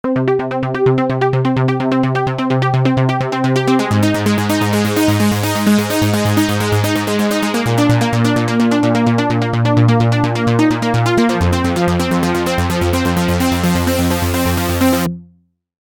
We start by detuning every single synth in the list.
The SuperSaw effect in mono.